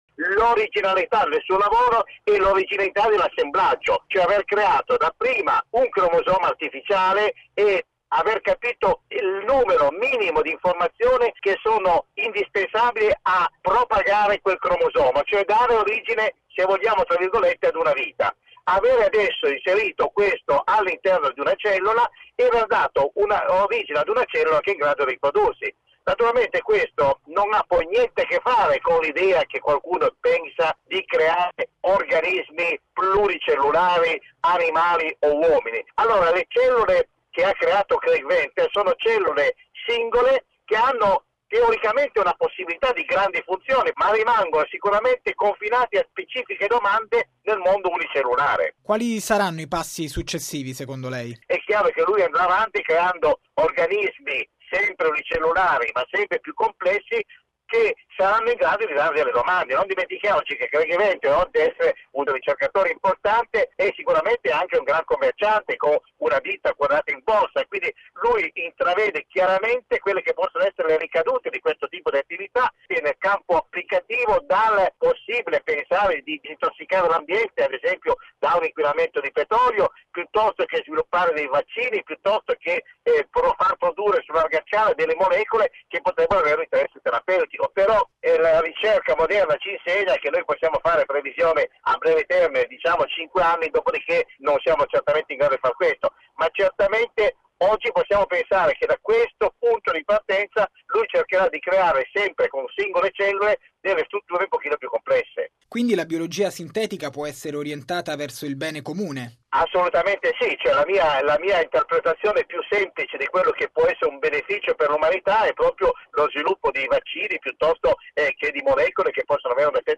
Ma qual è la reale portata dell’esperimento portato a termine dal gruppo americano che fa capo a Venter?